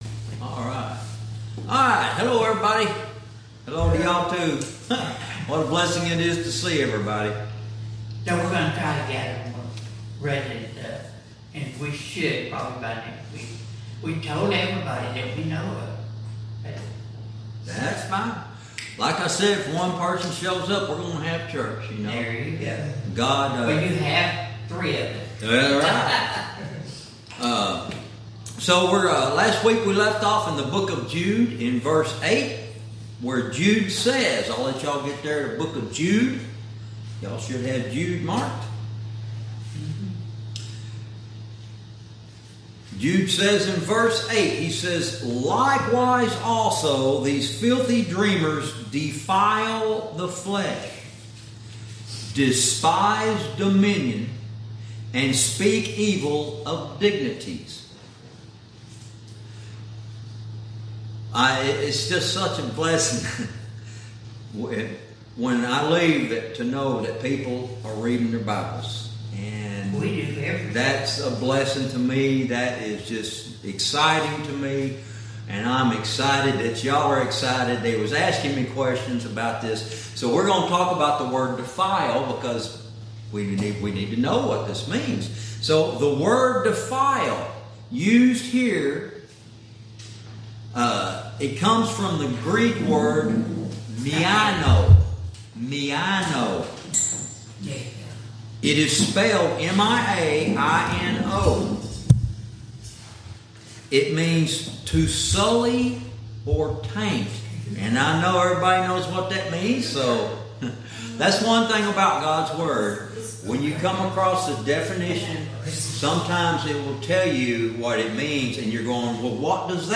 Verse by verse teaching - Lesson 25